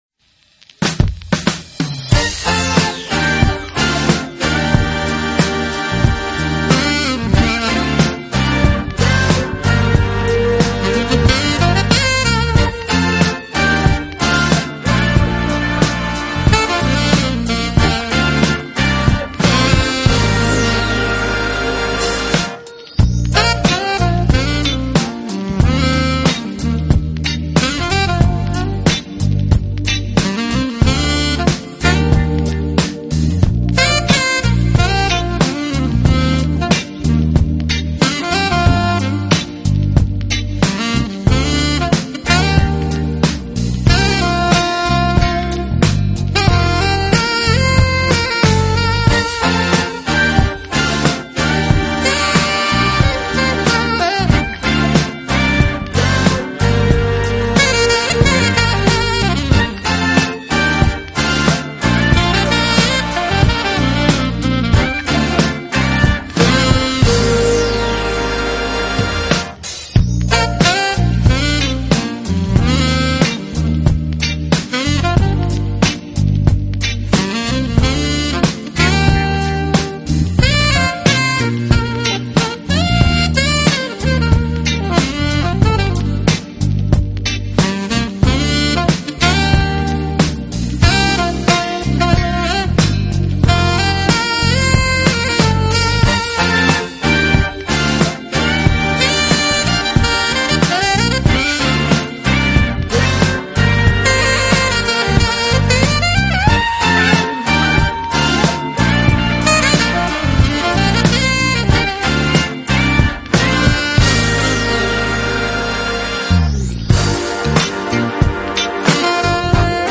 【爵士萨克斯】
音乐类型：Jazz
亮的金发和俏丽的面庞，她吹奏中音萨克斯的技巧也堪称一流。